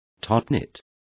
Complete with pronunciation of the translation of topknots.